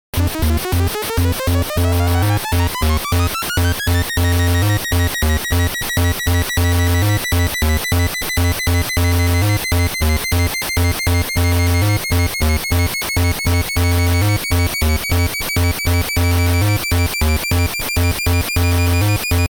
The famous chip is a mixture of digital and analogue technology with phase accumulated oscillators and analogue multimode NMOS filter.